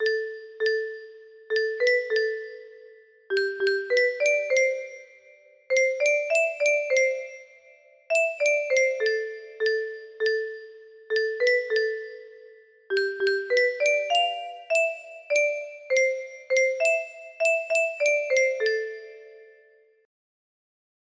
Specially Adapted For 20 Notes